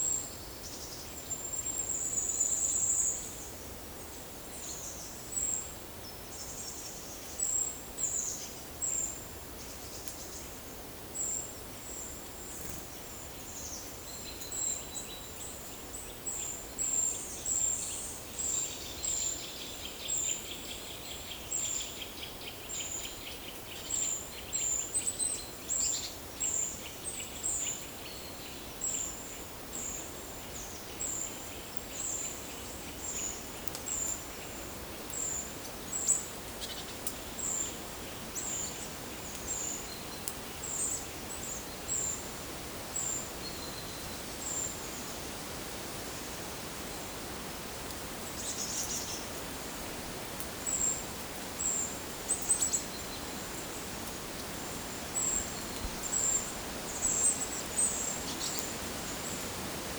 PEPR FORESTT - Monitor PAM - Renecofor
Certhia familiaris
Cyanistes caeruleus
Certhia brachydactyla
Sitta europaea
Regulus ignicapilla